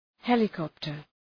Προφορά
{‘helə,kɒptər}